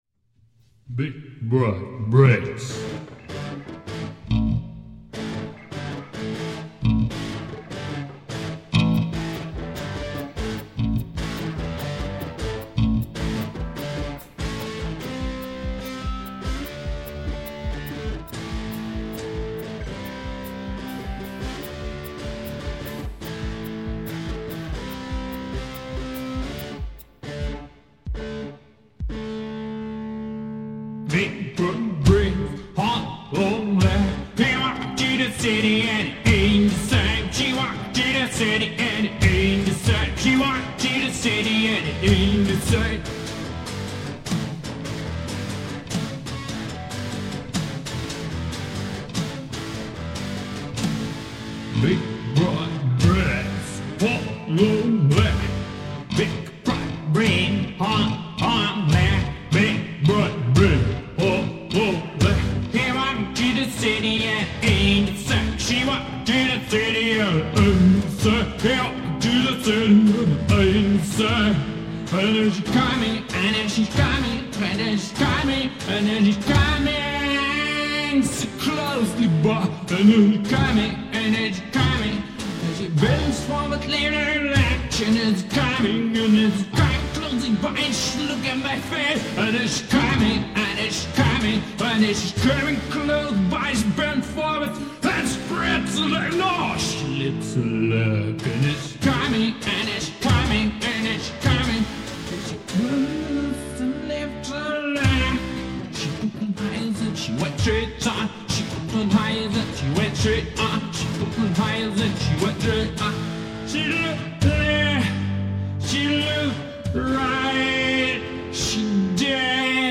Rock/Blues MP3